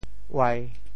Details of the phonetic ‘uai1’ in region TeoThew
IPA [uai]